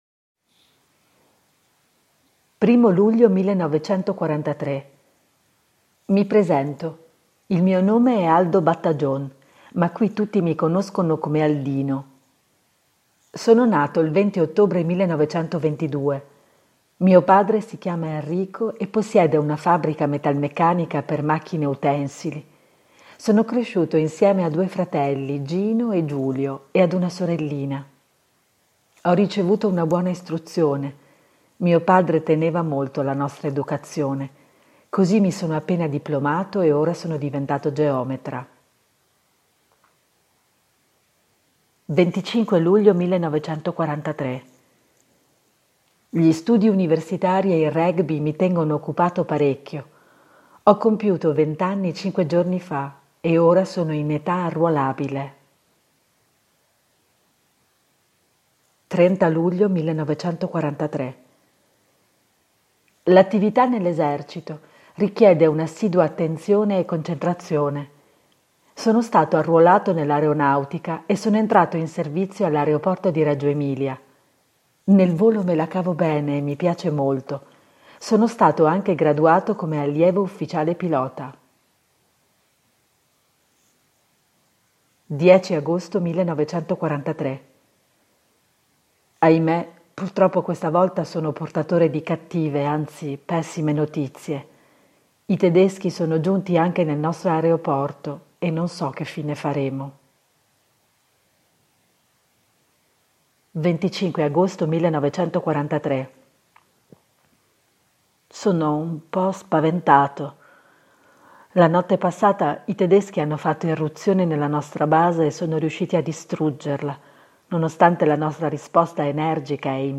Lettura di estratti da una memoria dattiloscritta